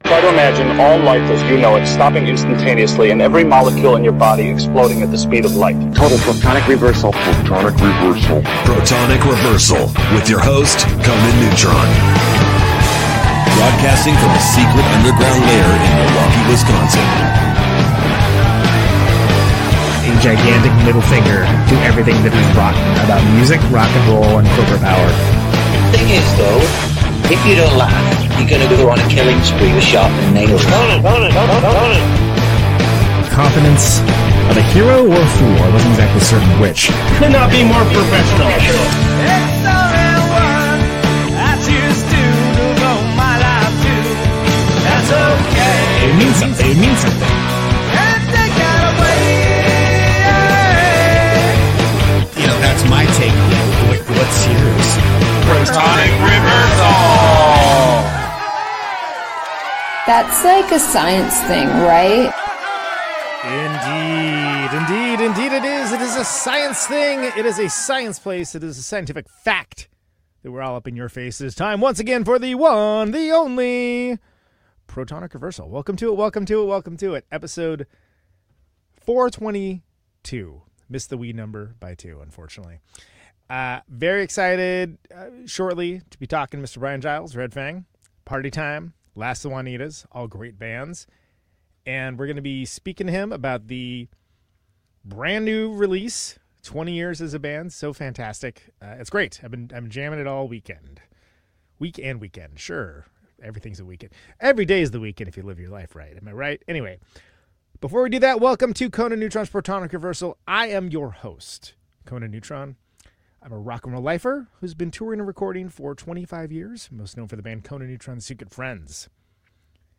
The finest interview/discussion show on music and musicians that mean a whole hell of a lot to certain people.